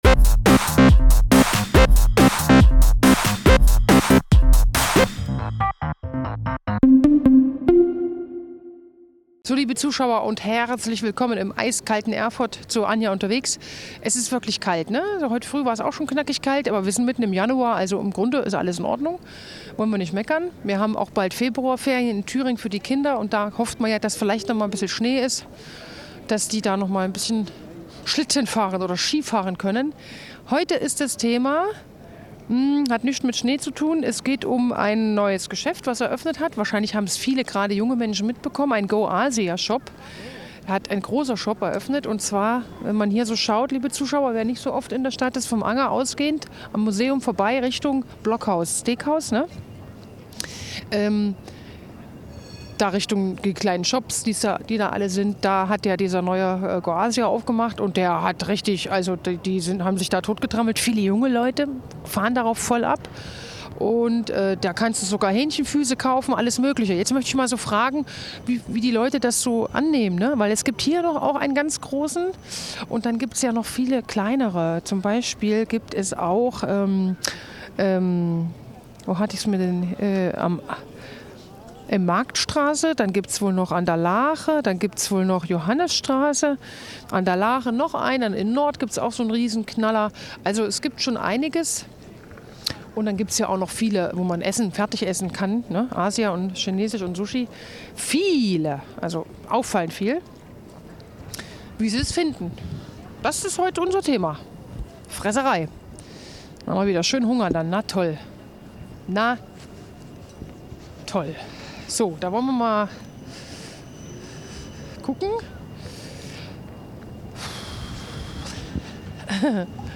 Katharina Schenk steht als Th�ringer Ministerin f�r Soziales, Gesundheit, Familie und Arbeit einem echten Mammut-Ressort vor! Die aktuelle Sendung �45 Minuten Th�ringen� mit der SPD-Politikerin f�hlt der �Brombeer-Koalition� auf den Zahn. Wie retten wir unsere Kliniken? Was muss sich f�r Th�ringens Pflegekr�fte �ndern?